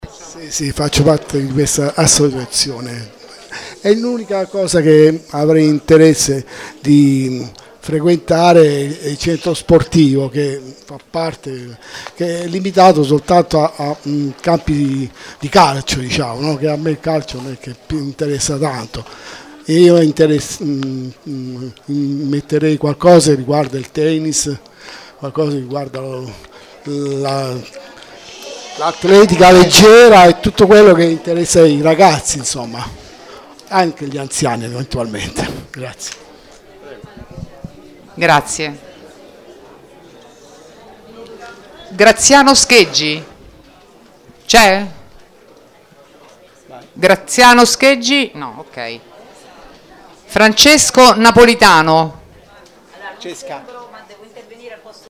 Interventi dei cittadini